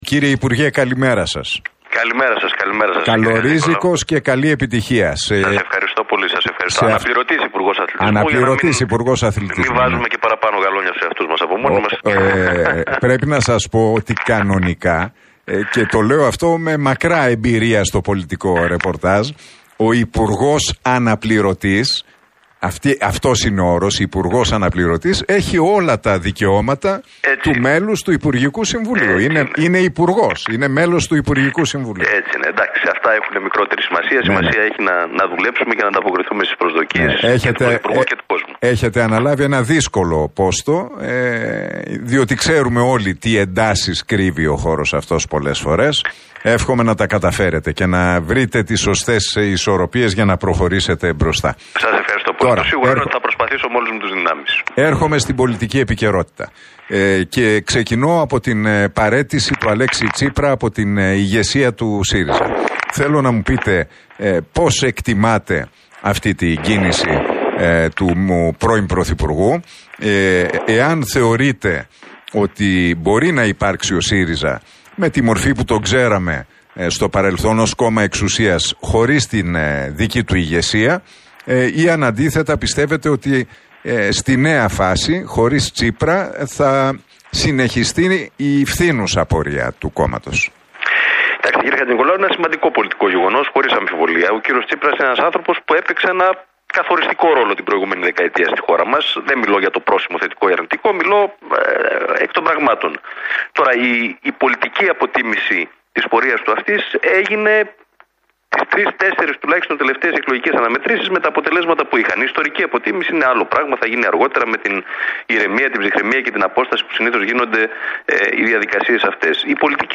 Ο Αναπληρωτής Υπουργός Αθλητισμού, Γιάννης Οικονόμου, σε συνέντευξη που παραχώρησε στο ραδιοφωνικό σταθμό, Realfm 97,8 και στην εκπομπή του Νίκου Χατζηνικολάου, τόνισε ότι η σεμνότητα, ο πραγματισμός και η αποτελεσματικότητα είναι τα κύρια χαρακτηριστικά που διέπουν την κουλτούρα διακυβέρνησης του Κυριάκου Μητσοτάκη και της Νέας Δημοκρατίας.